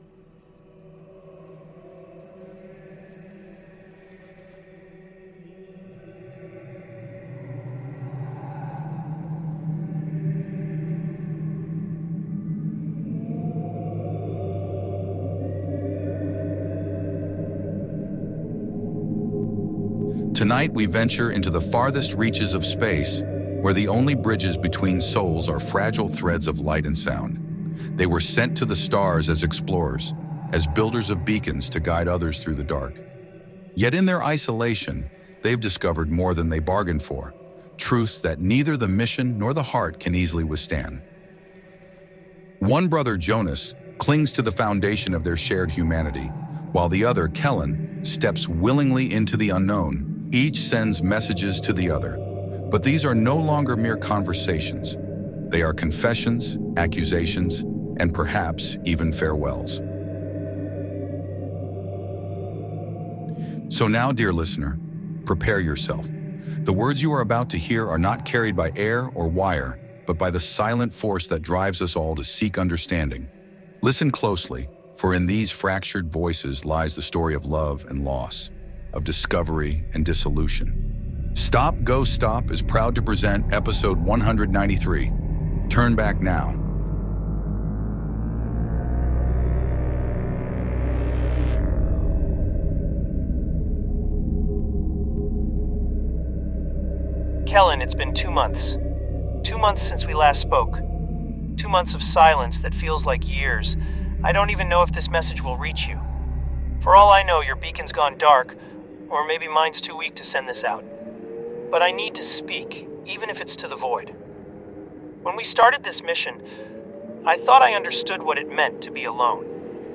stopGOstop is a podcast that explores the idea that sound recordings can act as sediment — an accumulation of recorded cultural material — distributed via rss feed, and listened to on headphones. Each episode is a new sonic layer, incorporating field recordings, plunderphonics, and electroacoustic sound, all composed together in one episode or, alternately, presented individually as striations.